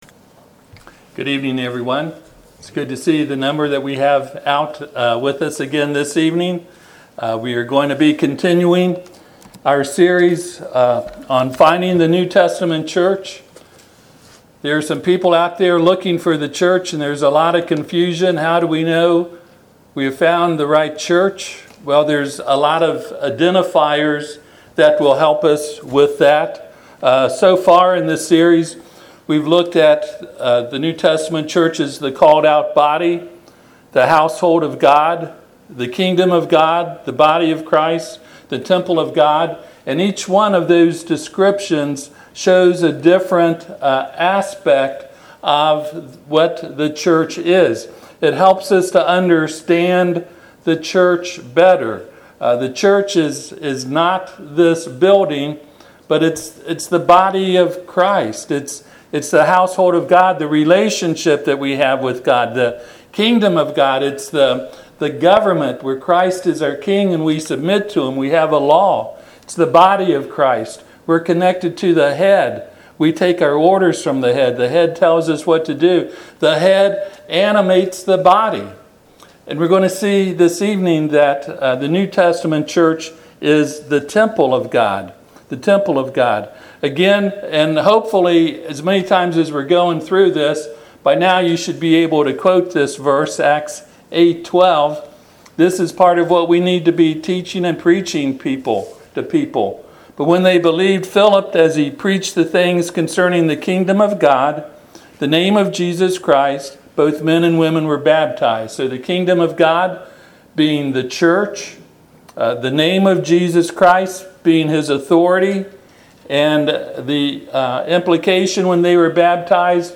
Service Type: Sunday PM Topics: Authority , Church , Pattern